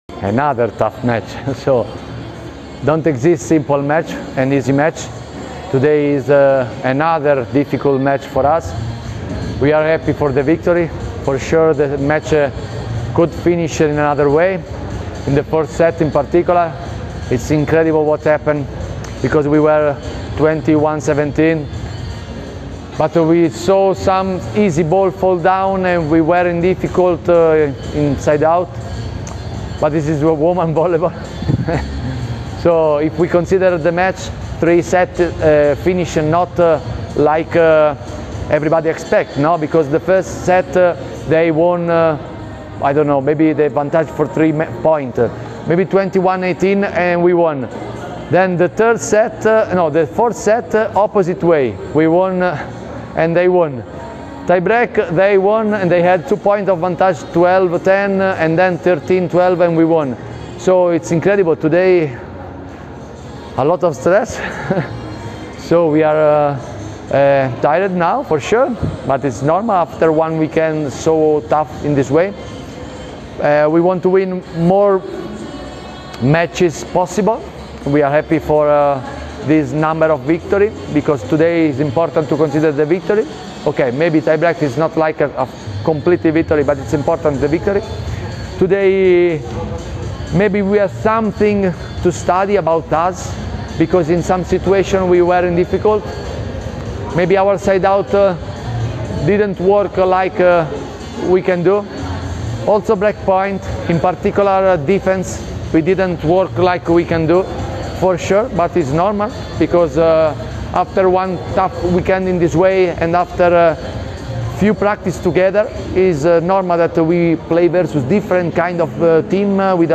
Izjava Danijelea Santarelija